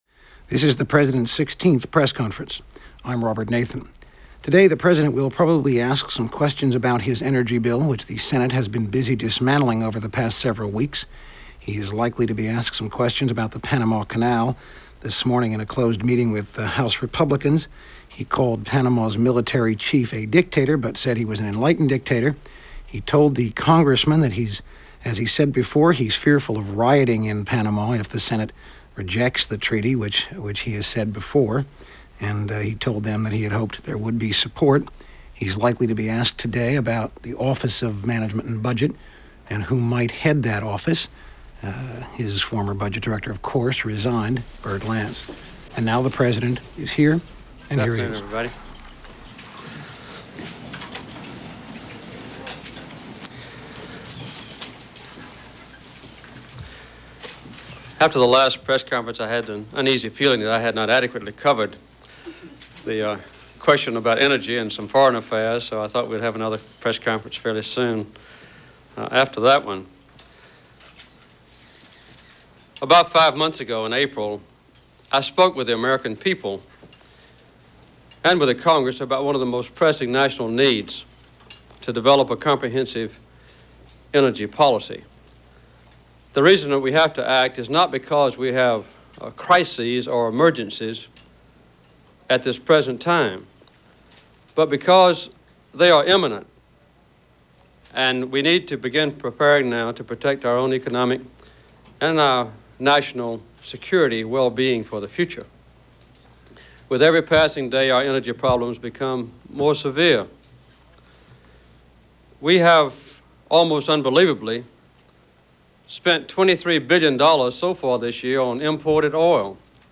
Jimmy Carter Press Conference #16 - September 29, 1977 - Past Daily: Looking Back - 16th Press Conferenc - carried by all networks